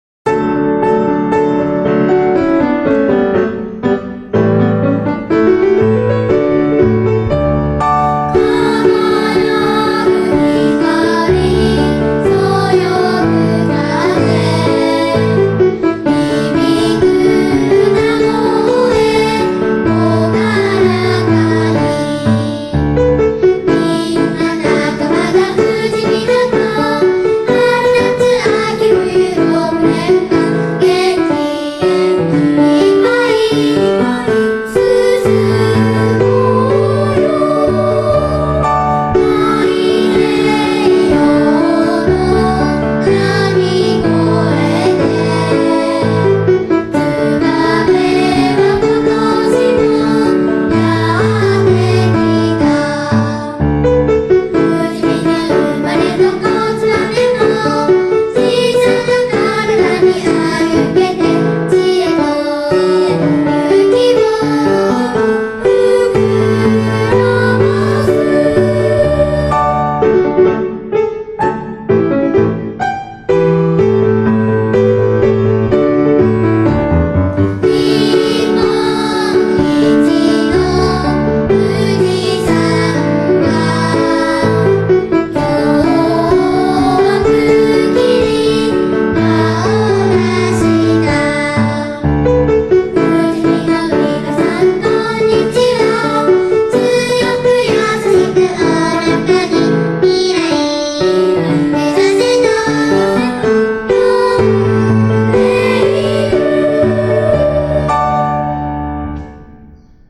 fujimi_school_song.wma